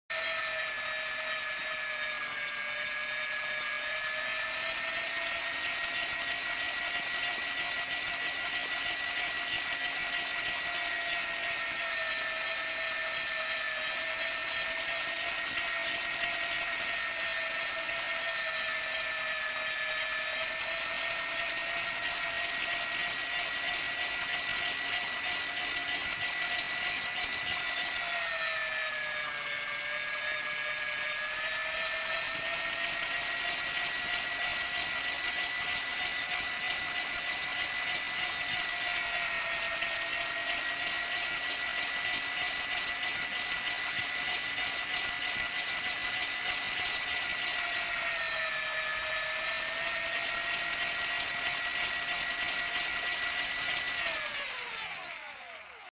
Звуки мясорубки
Мясорубка в действии (электрическая)